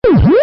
Pac-man eat fruit
pac-man-eat-fruit.mp3